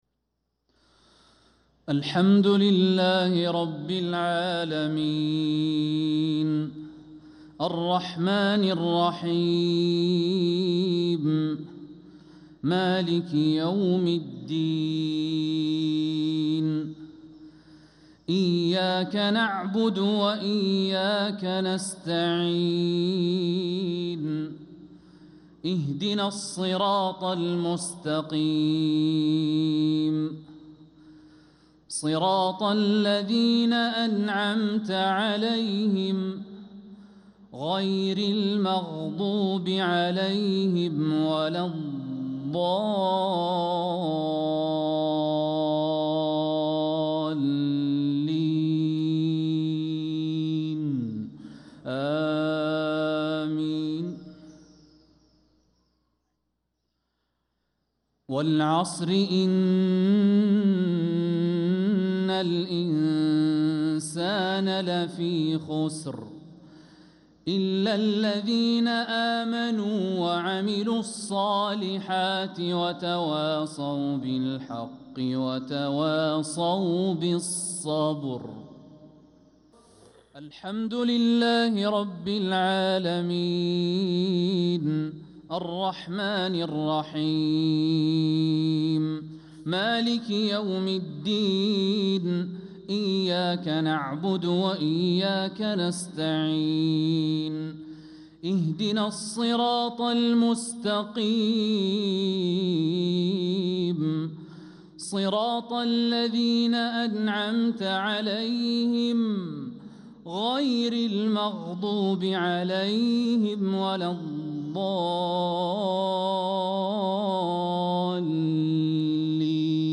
صلاة المغرب للقارئ الوليد الشمسان 25 ربيع الآخر 1446 هـ